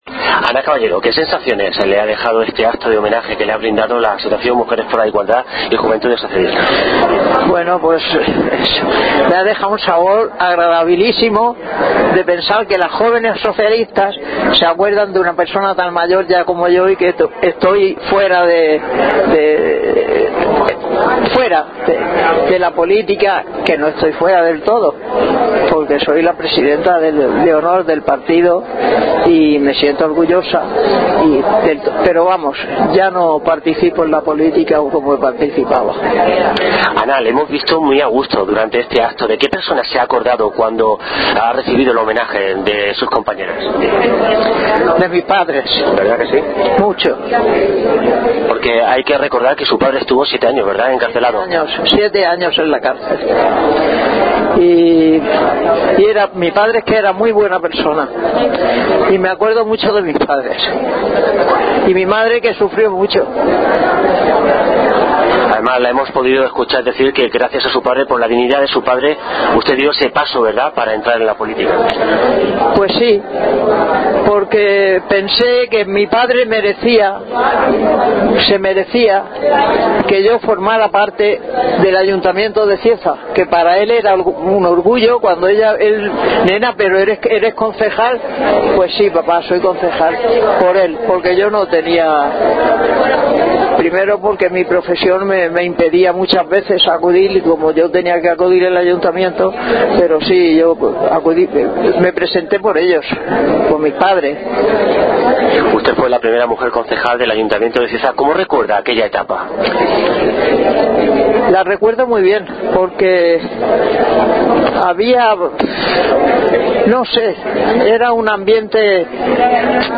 Fue un momento muy especial, no solo por la afluencia de p�blico, sino tambi�n porque sus palabras llegaron al coraz�n de cada uno de los asistentes. Con su manera de comunicar, reposada, c�lida y esperanzada
As� lo recoge el archivo sonoro de Radio Cieza Emisora Municipal durante el homenaje que propici� ese gozoso reencuentro.